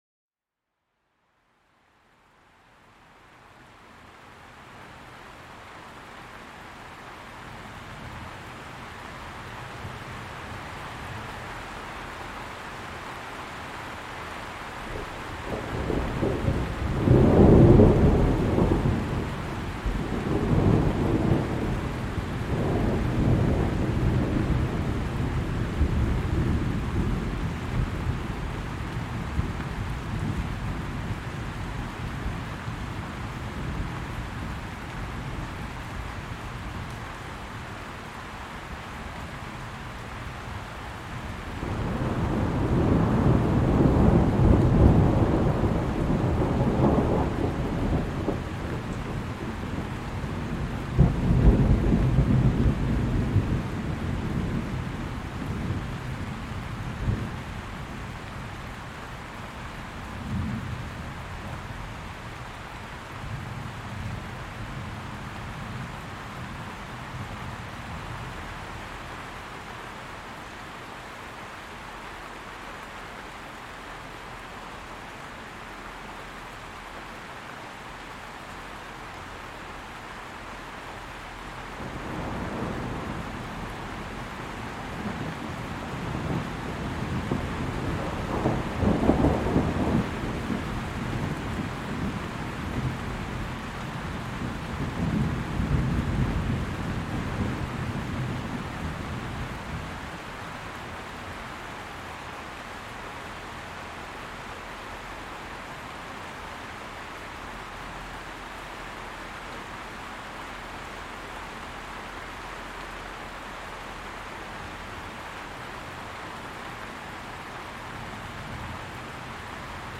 Laissez-vous bercer par le son d’un orage lointain qui apaise l’esprit et détend le corps. Les éclats de tonnerre et les gouttes de pluie créent une atmosphère immersive et relaxante.